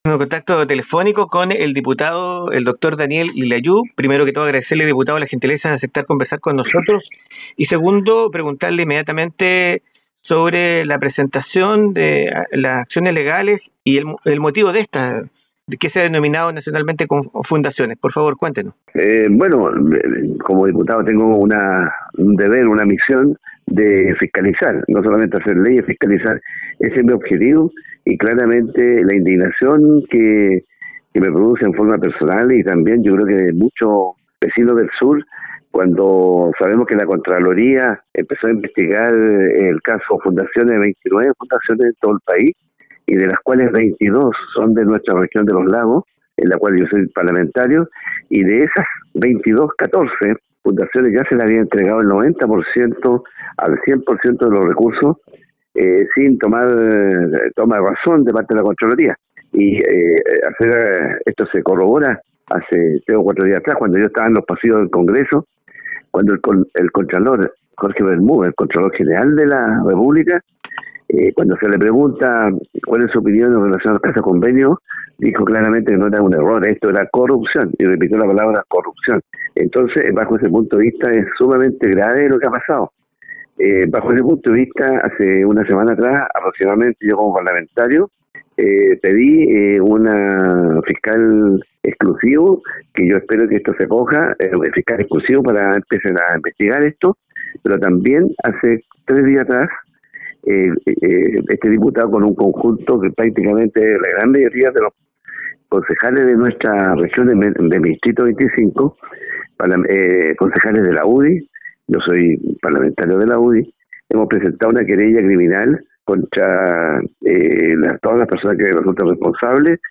🔵En conversación con este medio El diputado Daniel Lilayú (UDI) se refirió a la querella criminal que presentó contra los involucrados en la entrega de fondos públicos a 22 fundaciones, en un caso que está siendo investigado por posible corrupción.